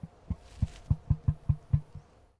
Звуки включают крики, шум шагов и другие характерные для эму аудиоэффекты.
Звуки эму Dromaius novaehollandiae